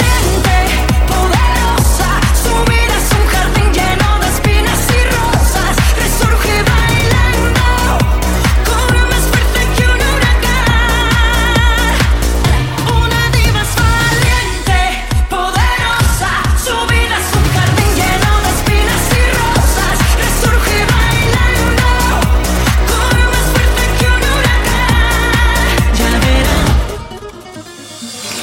Genere: dance,disco,pop,rock,ballad,techno,folk,etnic